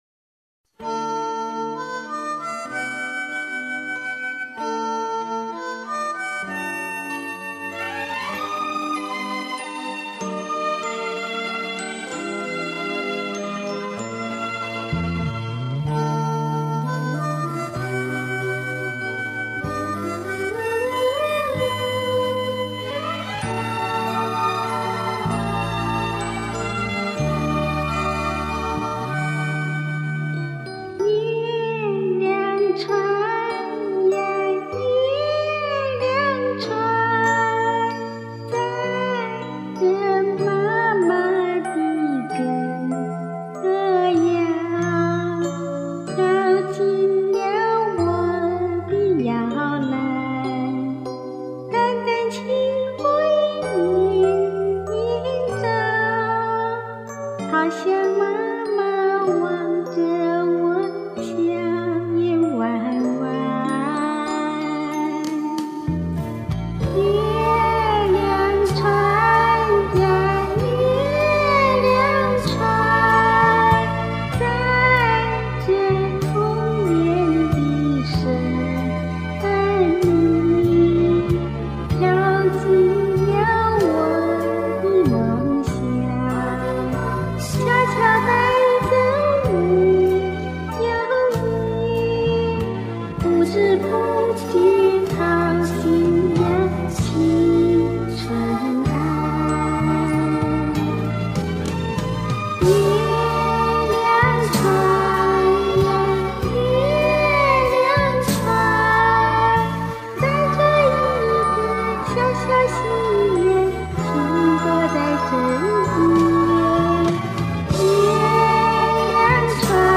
这也怪不了青蛙，您那声音太像少女啦！奶声奶气的纯真少女啊！
奶声奶气的纯真少女!!!!!!!!!!!!!